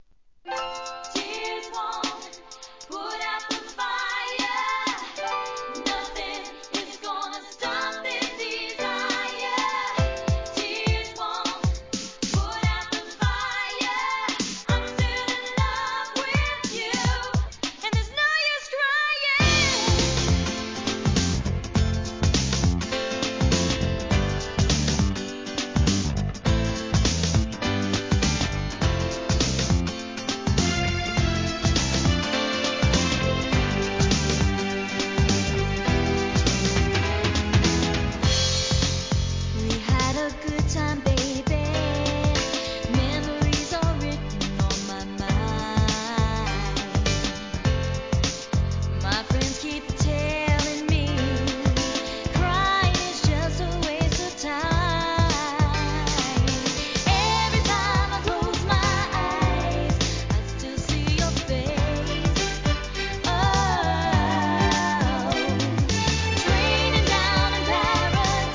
HIP HOP/R&B
NEW JACK SWING, グランドビート